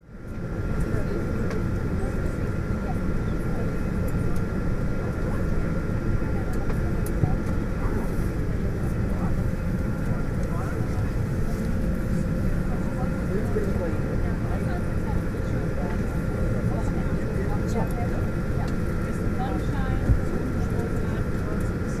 Звуки аэропорта, атмосфера
Шум в салоне самолета перед взлетом